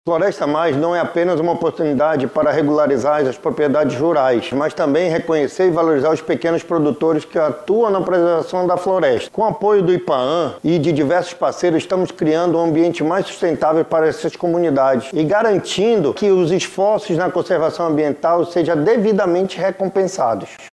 Gustavo Picanço, diretor-presidente do Instituto de Proteção Ambiental do Amazonas – Ipaam, explica que o programa é um importante instrumento de valorização das boas práticas ambientais.